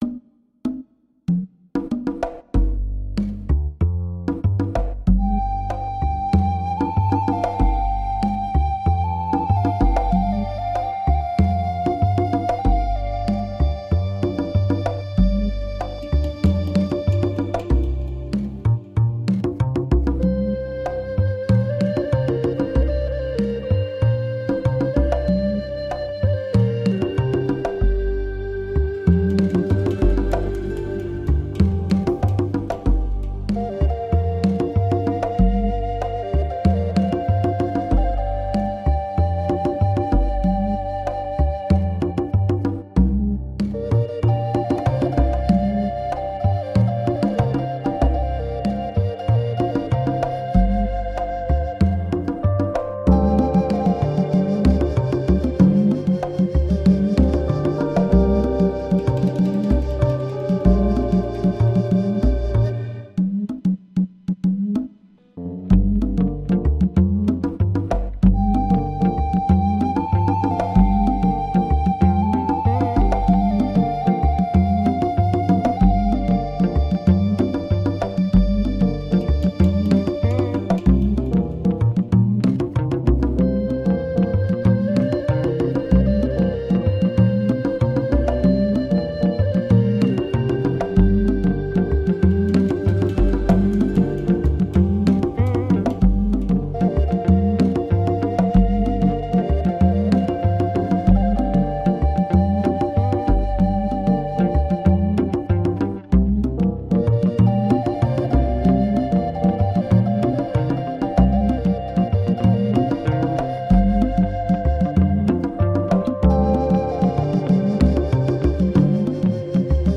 Groove soaked ambient chill.